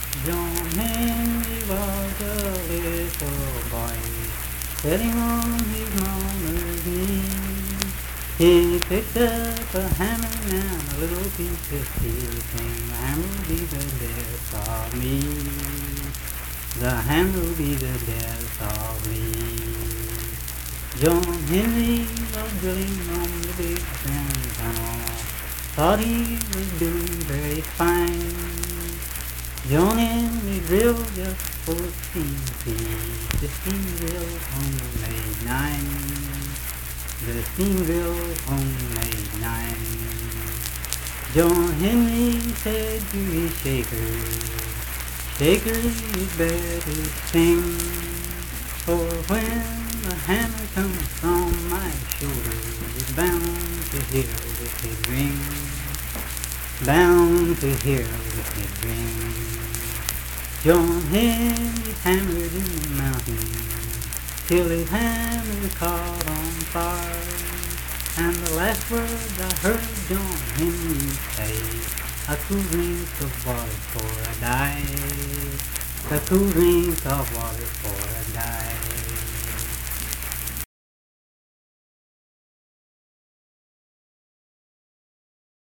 Unaccompanied vocal music
Verse-refrain 4(5w/R).
Performed in Frametown, Braxton County, WV.
Voice (sung)